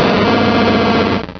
Cri d'Ursaring dans Pokémon Rubis et Saphir.